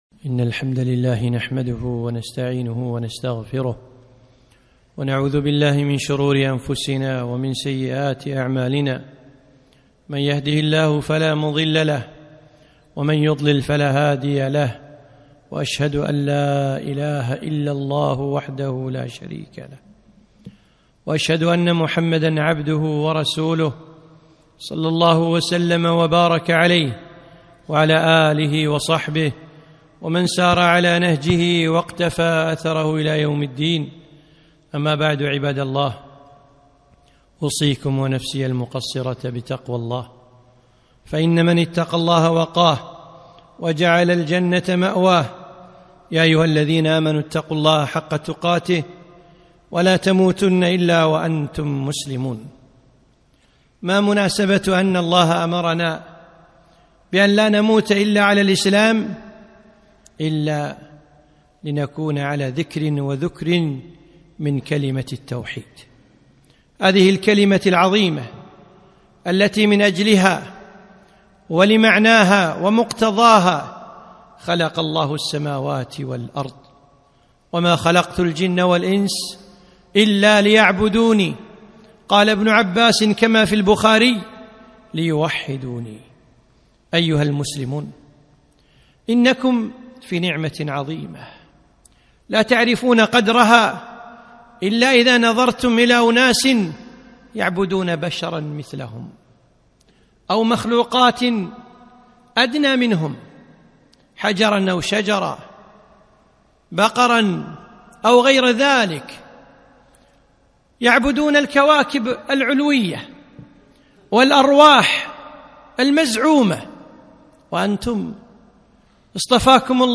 خطبة - كلمة التوحيد